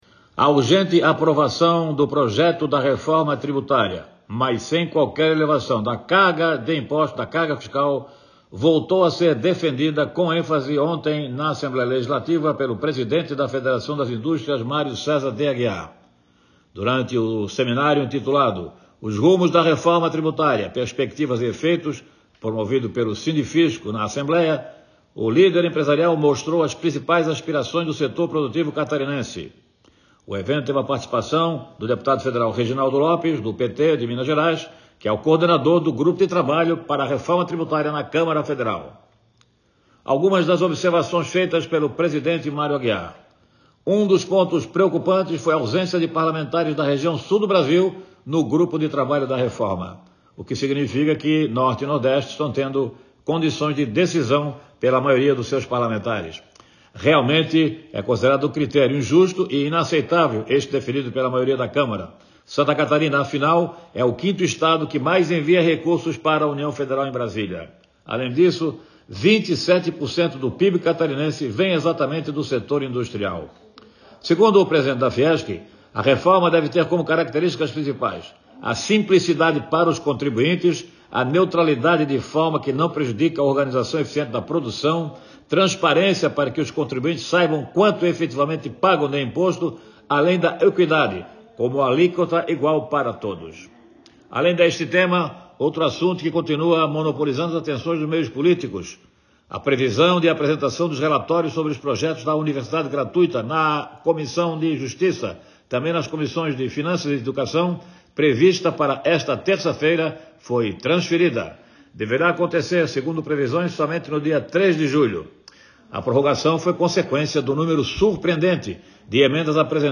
Jornalista comenta que um seminário promovido pelo Sindifisco de Santa Catarina discutiu amplamente as expectativas e os efeitos da Reforma Tributária no Estado
Confira o comentário na íntegra